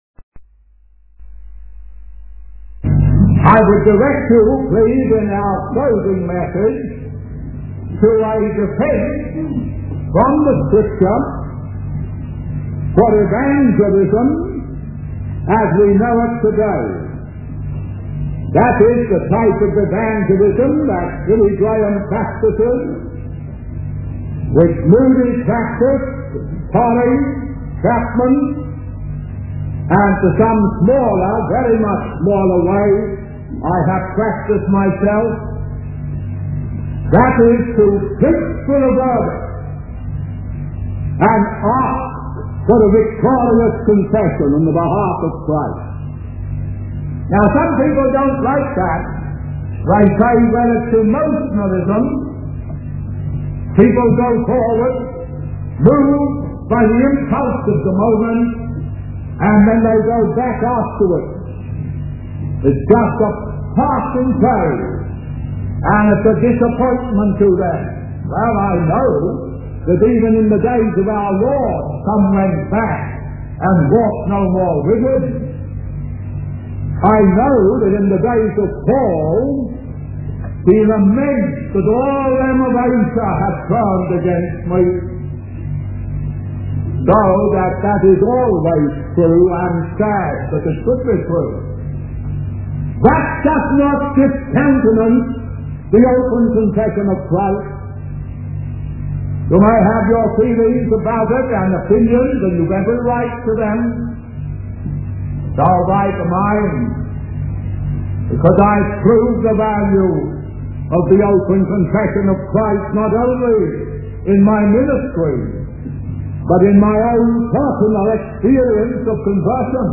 In this sermon, the preacher emphasizes the importance of God's involvement in the lives of people. He highlights the need for God to fight for and guide individuals, as well as the role of the Messiah in bringing salvation.